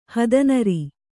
♪ hadanari